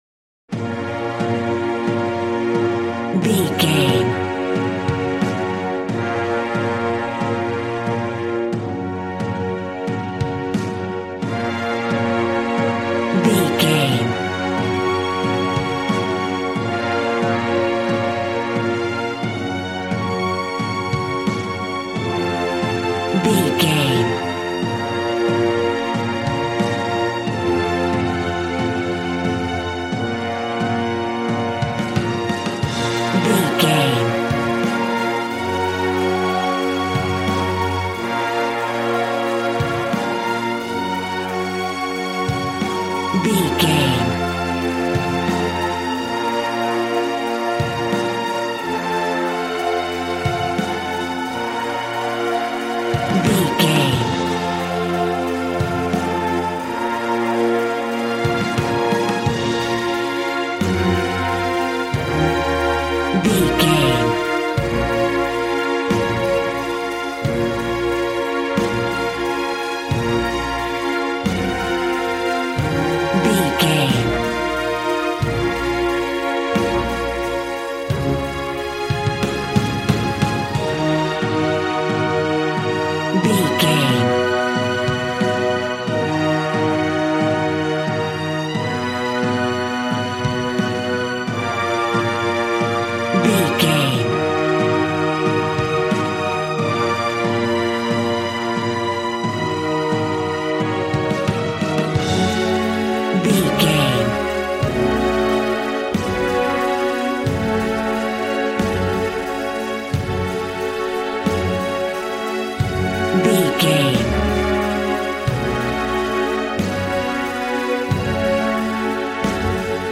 Aeolian/Minor
B♭
dramatic
strings
violin
brass